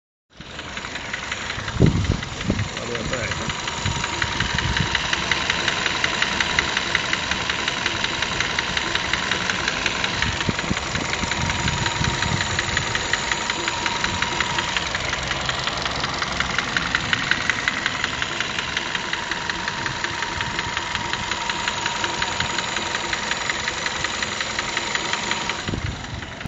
kia ceed 1.6 benzin nel ez csatogos hang meg belefer az elfogadhato mertekbe vagy mar inkabb a problemas kategoriaba tartozik.? 5w40 -et kapott a motor eddig.
10 perc utan halkabb lesz a motor de teljesen nem szunik meg a csattogas.